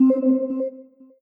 Call_Ended.964311c451c17cfe3433.mp3